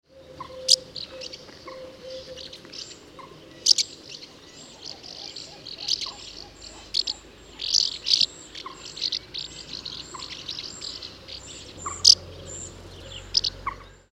Hirondelle de fenêtre
Delichon urbicum
Chant
Hirondelle_de_fenetre.mp3